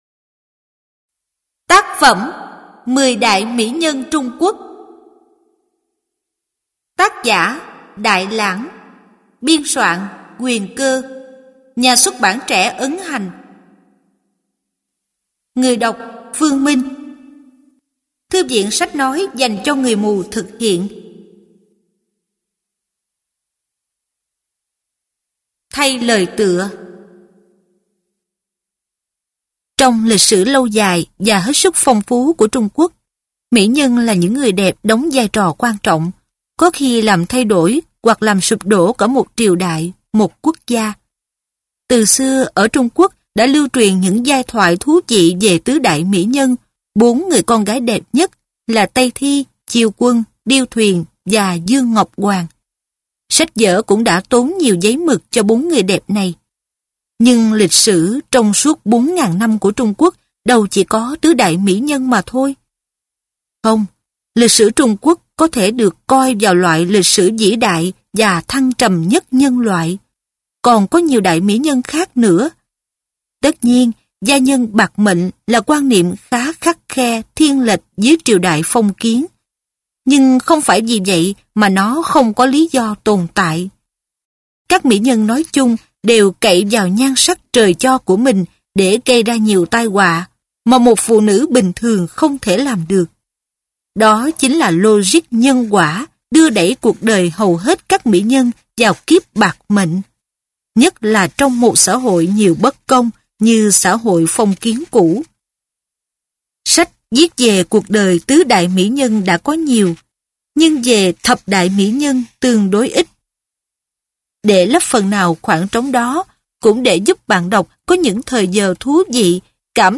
Sách nói | Thập đại Mĩ nhân Trung Hoa